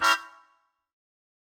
GS_MuteHorn-Edim.wav